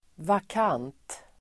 Ladda ner uttalet
vakant adjektiv (om tjänst), vacant [of a job] Uttal: [vak'an:t] Böjningar: vakant, vakanta Synonymer: ledig Definition: inte tillsatt, ledig Exempel: tjänsten har blivit vakant (the position has fallen vacant)